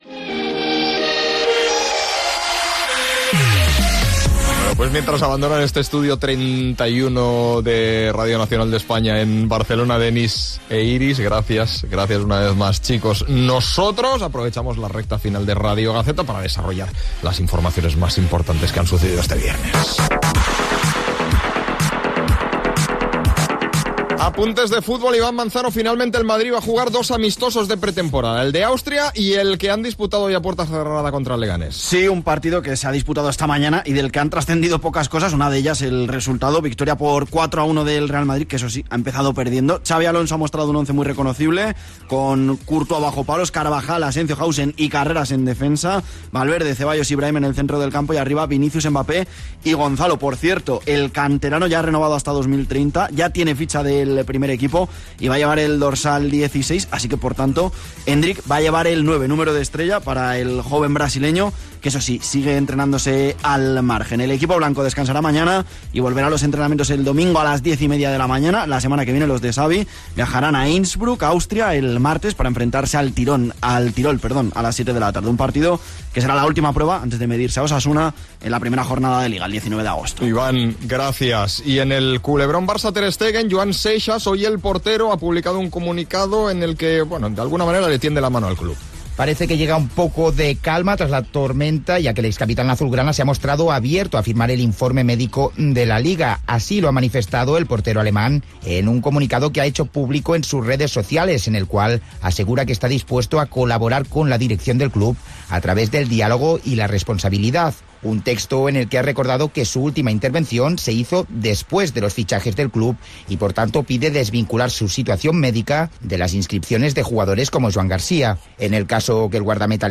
Gènere radiofònic Esportiu